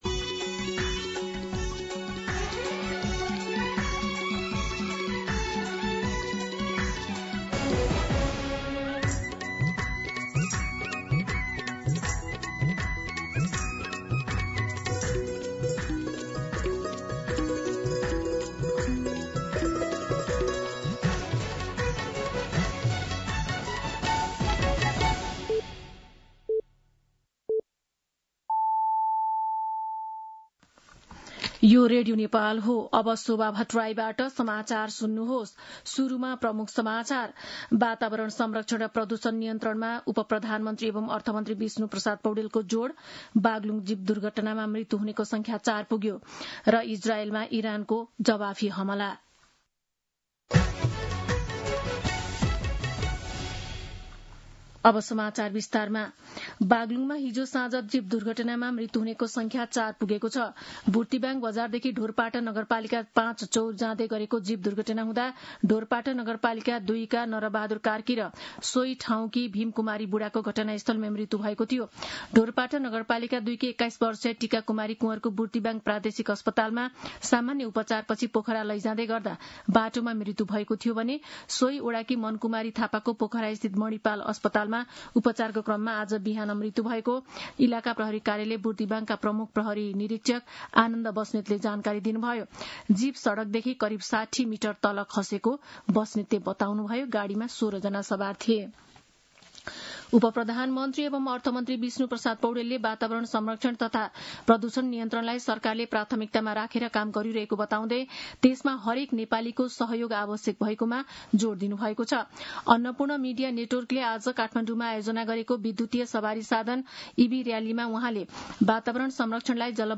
An online outlet of Nepal's national radio broadcaster
दिउँसो ३ बजेको नेपाली समाचार : ३१ जेठ , २०८२
3pm-News-02-n-31.mp3